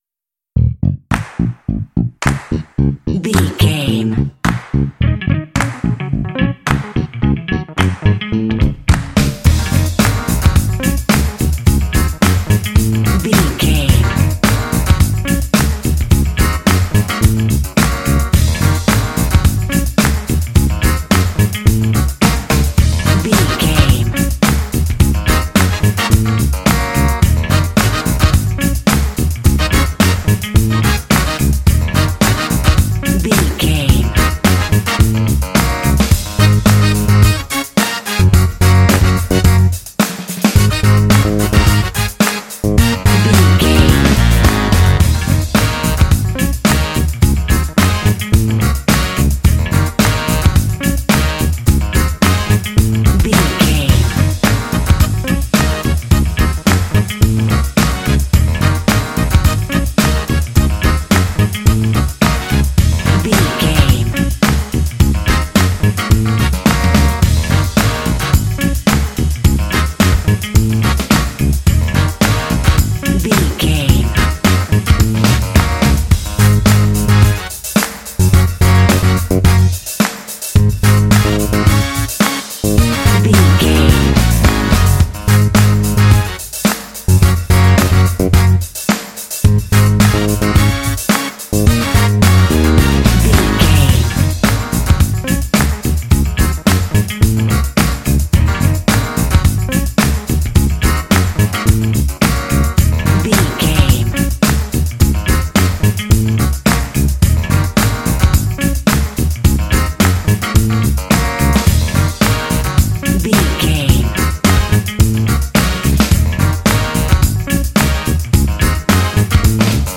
Uplifting
Dorian
E♭
groovy
smooth
bass guitar
electric guitar
drums
synthesiser
percussion
brass